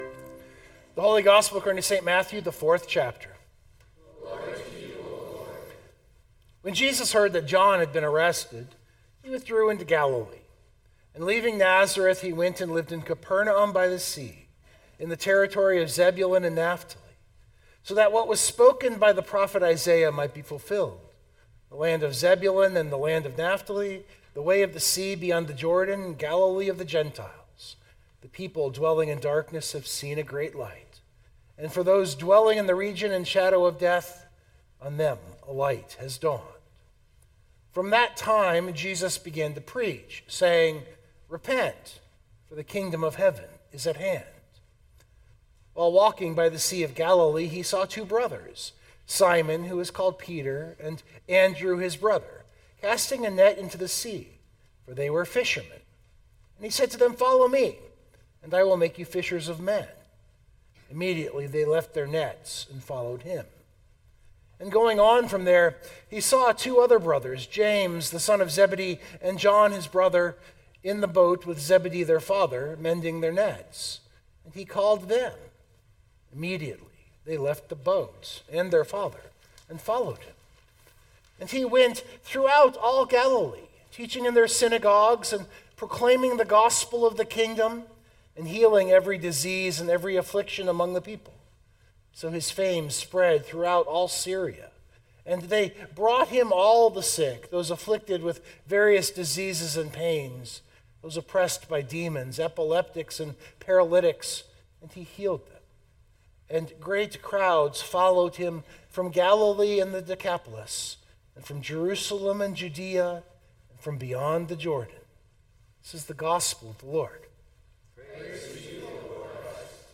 This sermon is a meditation on how those first words contain the entire story that follows right down to us.